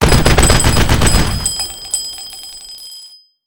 machine2.wav